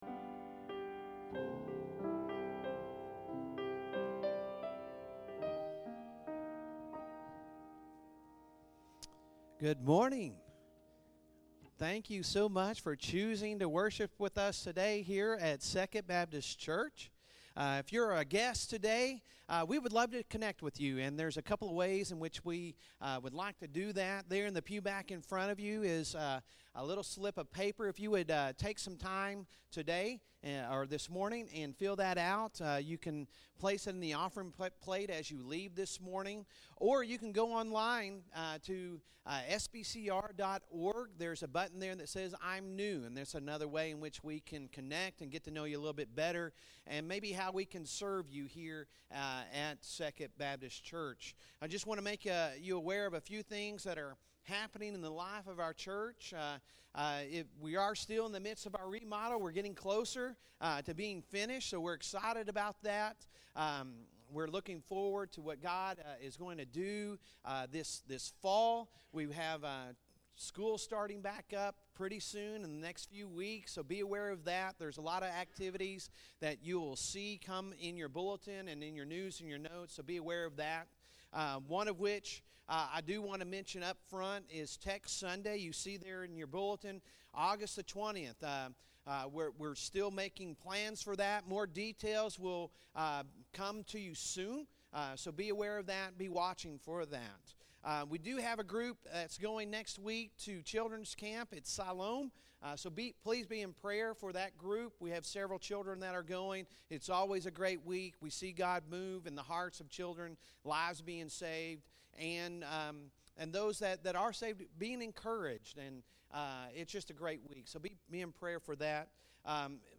Sunday Sermon July 23, 2023